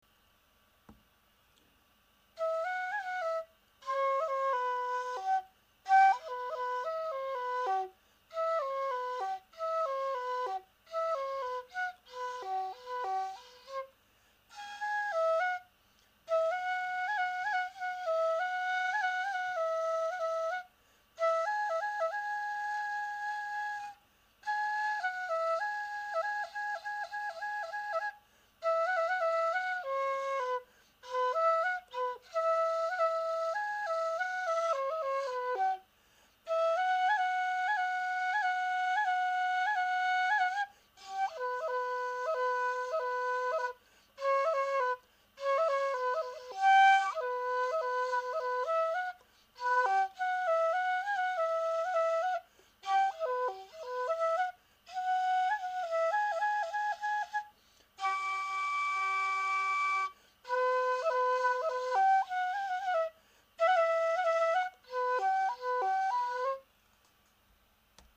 笛は未熟者で恐縮ですが、私の笛を録音させていただきました。
笛のメロディ